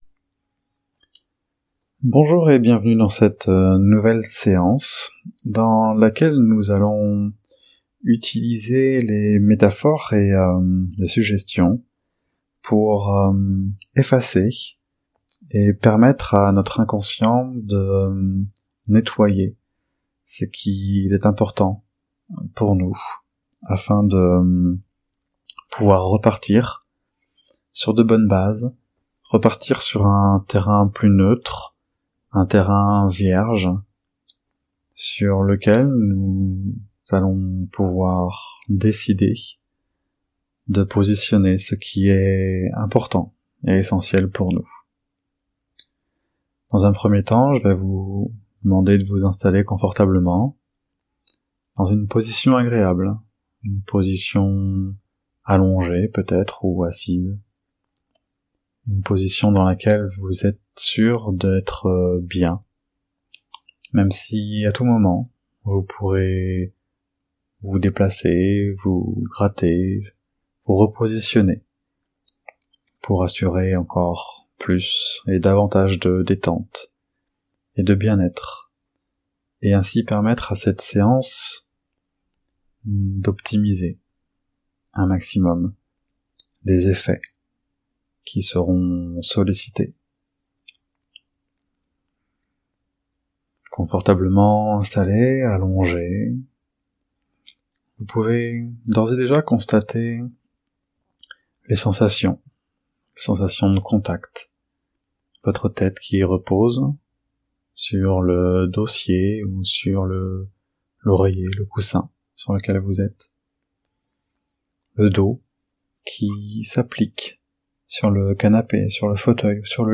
Hypnose Ericksonienne de 25 minutes pour effacer les schémas émotionnels négatifs et reconstruire sur des bases positives.
Laissez-vous guider par la voix, et plongez dans un état de relaxation profonde.
Sans bande son
Hypnose-Tableau-noir-sans-fond-sonore.mp3